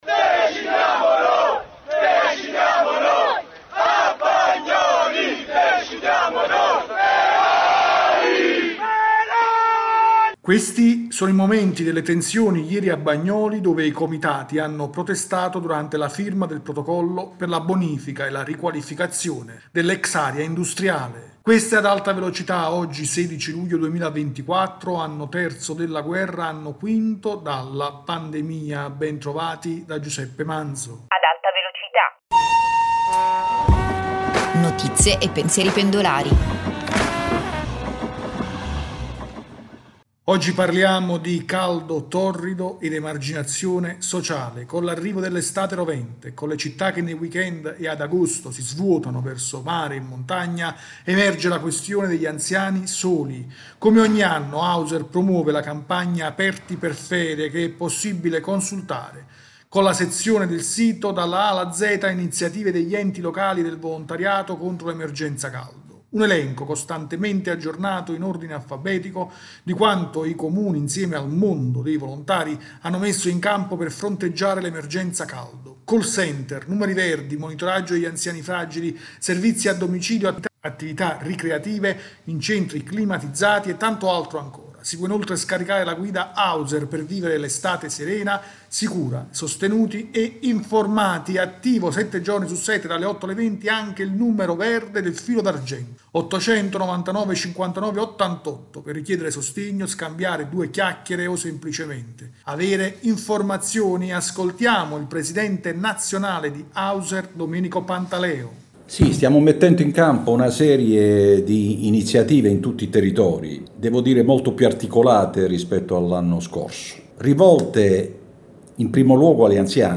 [Apertura: Questi sono i momenti delle tensioni ieri a Bagnoli dove i comitati hanno protestato durante la firma del protocollo per la bonifica e la riqualificazione dell’ex area industriale.
rubrica quotidiana